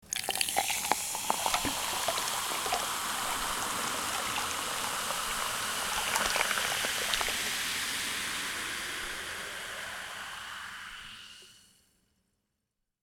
Download Free Liquid Sound Effects | Gfx Sounds
Pouring-sparkling-water-fizz-drink-3.mp3